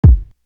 Definition Kick.wav